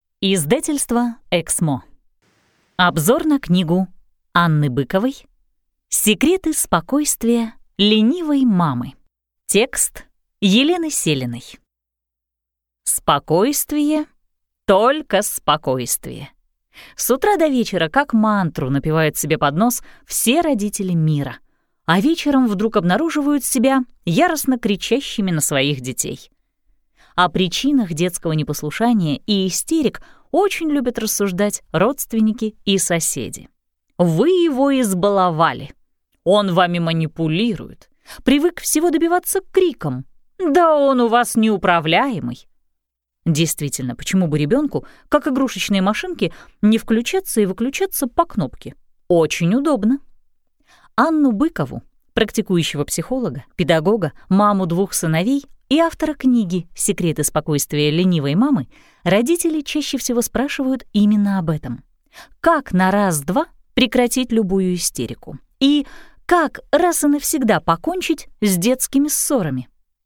Аудиокнига Саммари книги «Секреты спокойствия „ленивой мамы“» | Библиотека аудиокниг